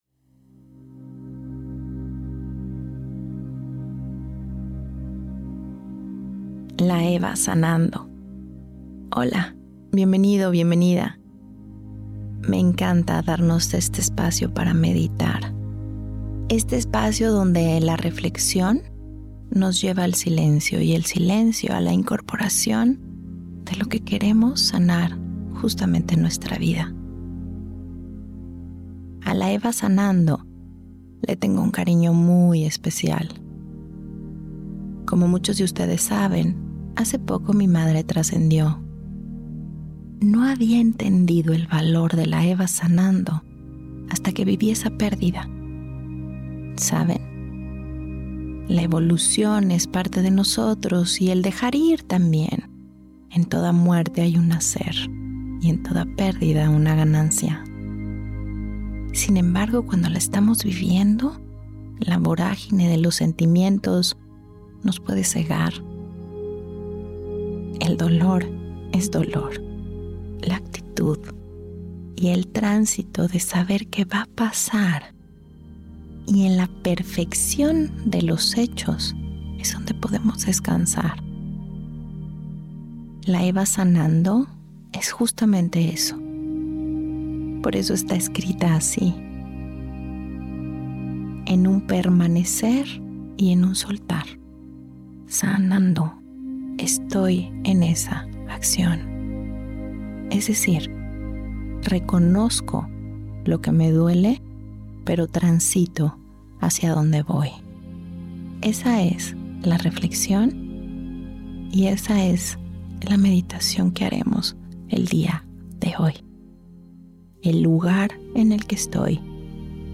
Meditaciones Eva Sanando Esta Eva te conecta con esa parte de ti conciente de tus cicatrices, pero sintiendo el nacimiento de una nueva vida.